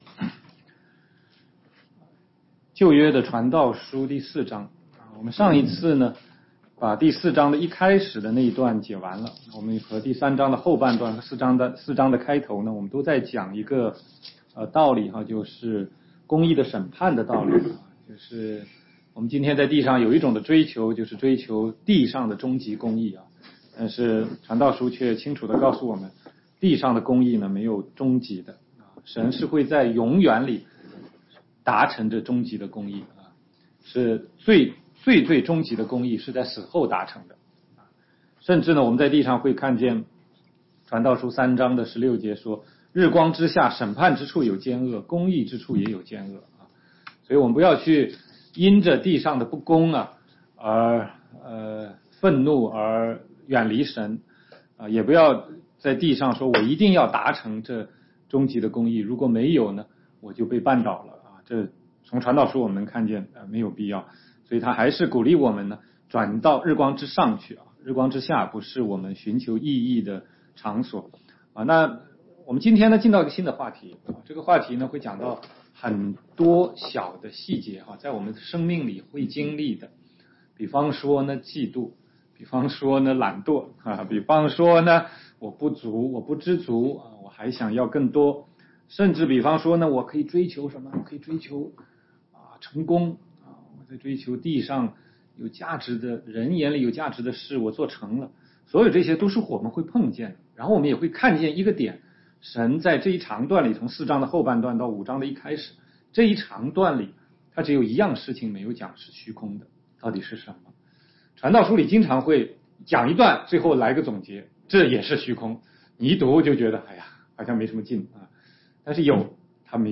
16街讲道录音 - 从传道书看人生的意义：从虚空到记念造你的主（5）